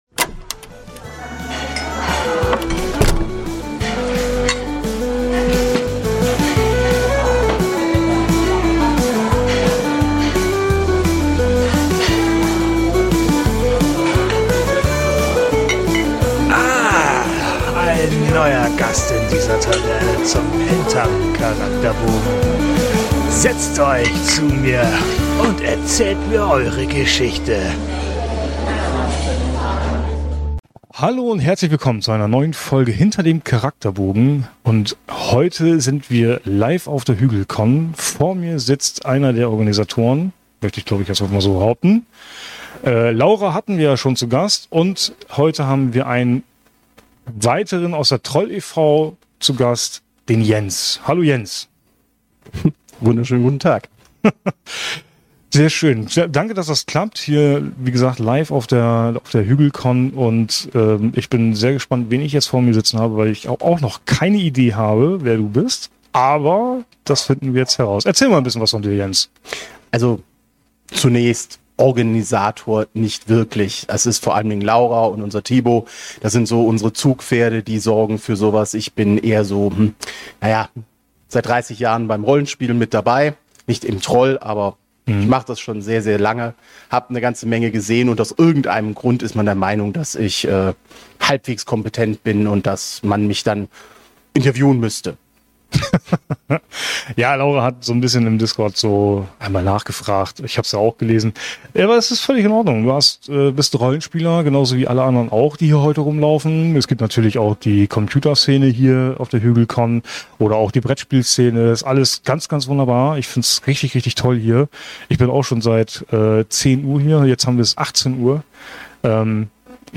Da es an dem Tag unglaublich warm war und wir im dritten Stock unterm Dach saßen, ist diese Folge etwas kürzer als sonst geworden.